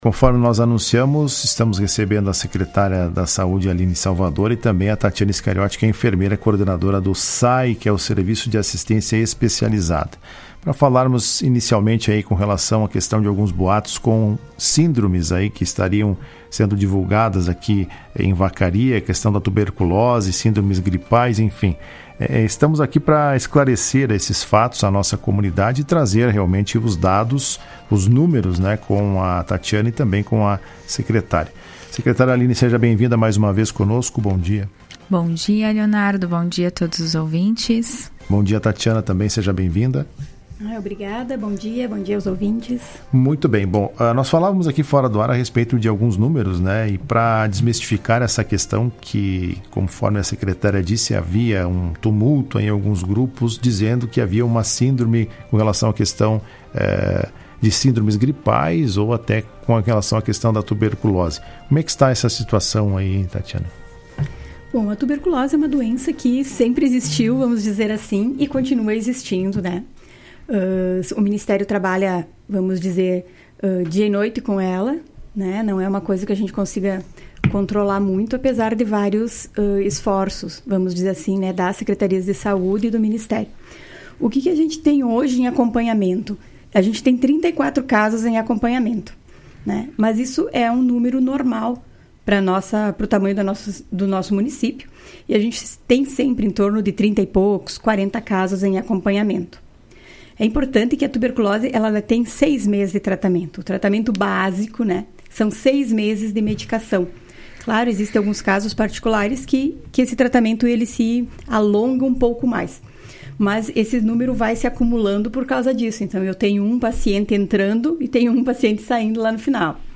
Em entrevista ä Rádio Esmeralda nesta quinta-feira